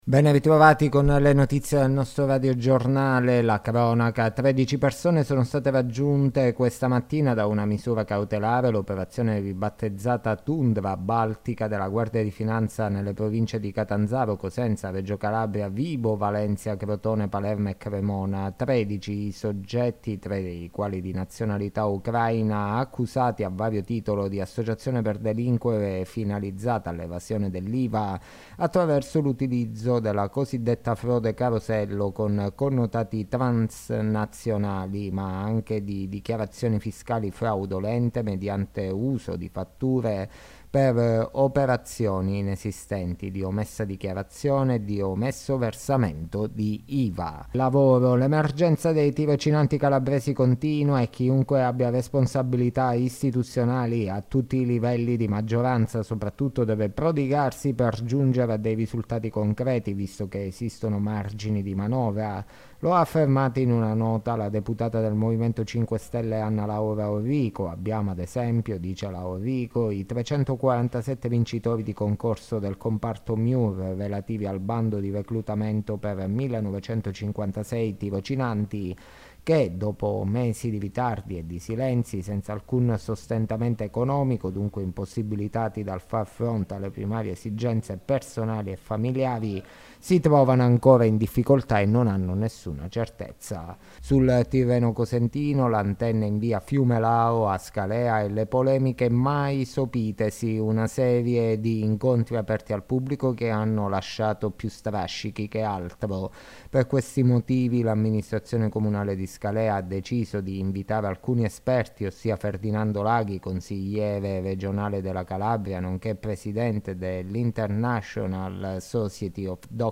Le notizie del giorno di Martedì 24 Ottobre 2023